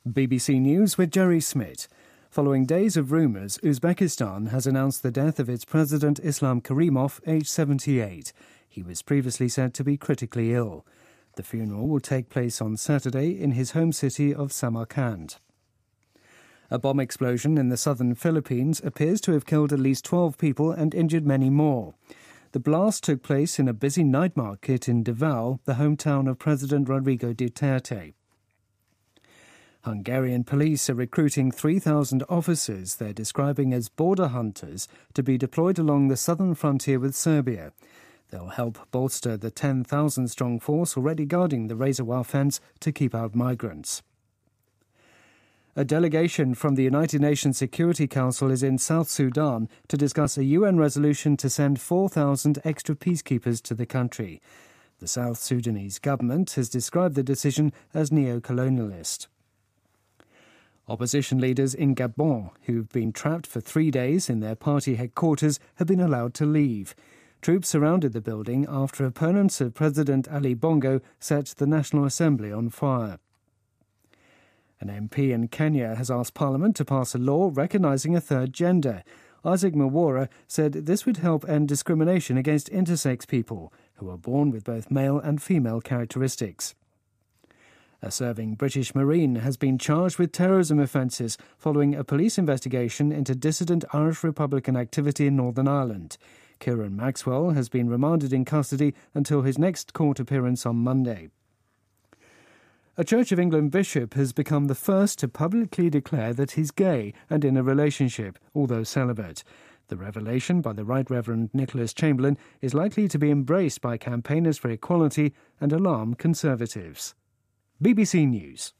日期:2016-09-05来源:BBC新闻听力 编辑:给力英语BBC频道